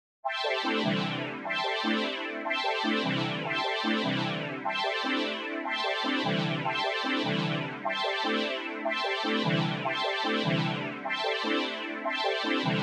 Weird Loop Sound Button - Free Download & Play